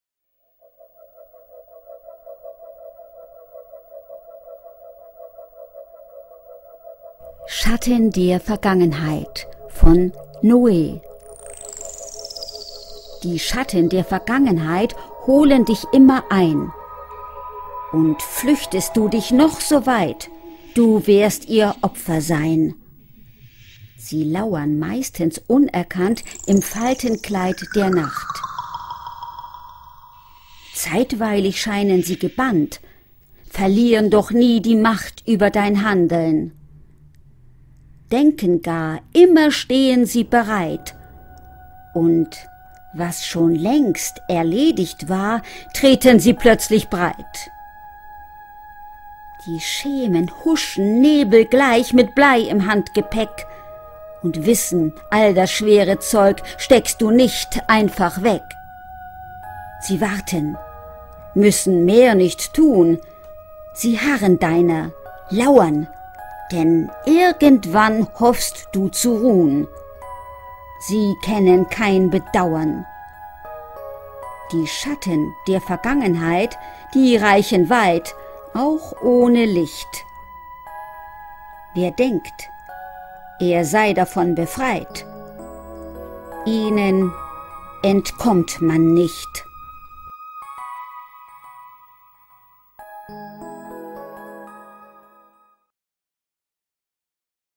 Rezitation: